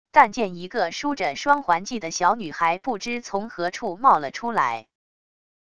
但见一个梳着双环髻的小女孩不知从何处冒了出来wav音频生成系统WAV Audio Player